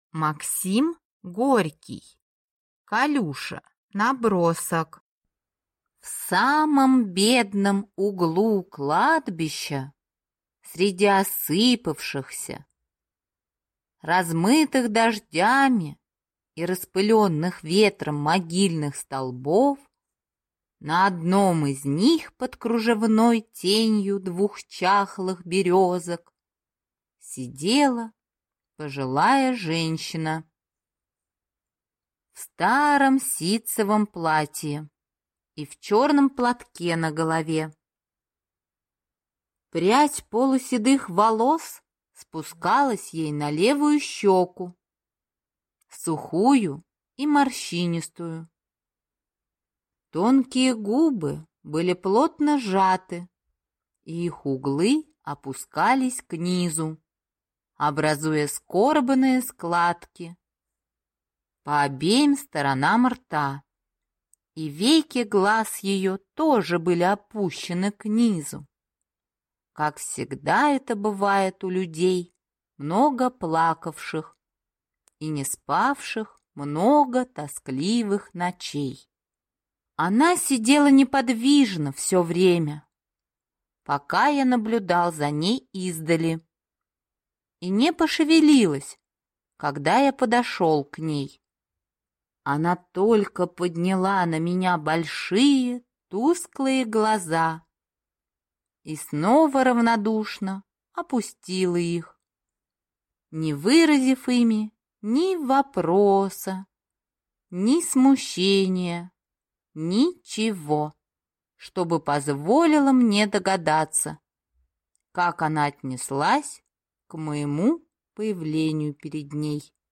Аудиокнига Колюша | Библиотека аудиокниг